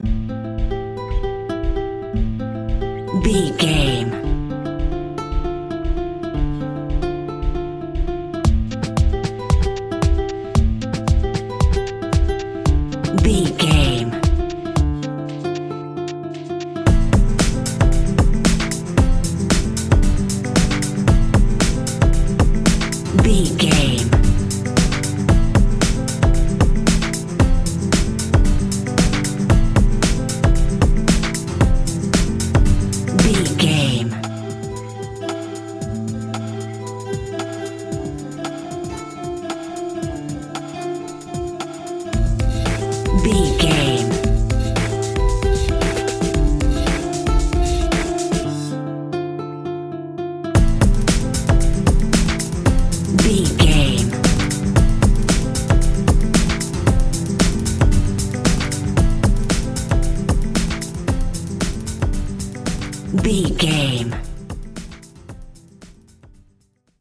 Aeolian/Minor
calm
repetitive
soft
acoustic guitar
drum machine
strings
electronic
new age
techno
trance
drone
glitch
synth lead
synth bass